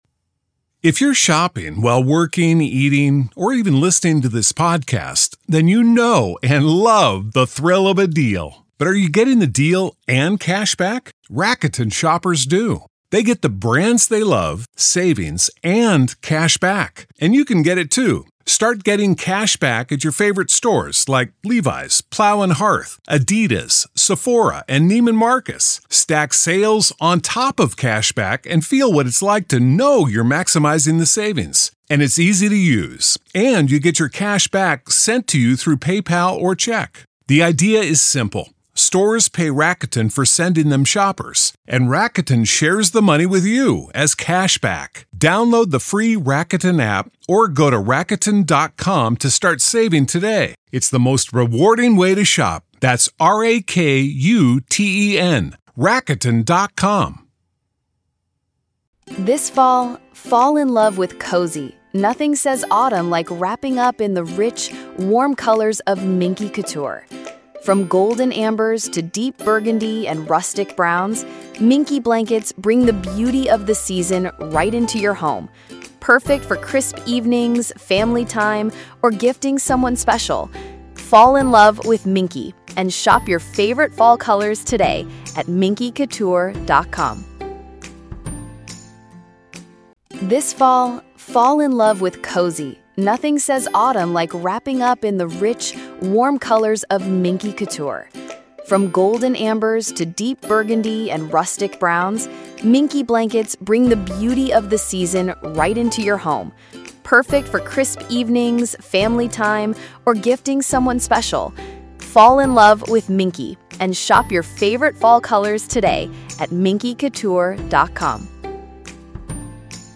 True Crime Today | Daily True Crime News & Interviews / The Sheriff, the Judge & the Courthouse Murder: What Really Happened in Letcher County?